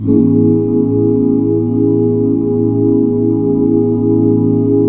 AMAJ7 OOO -L.wav